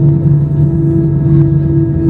update with sounds from glasgow flight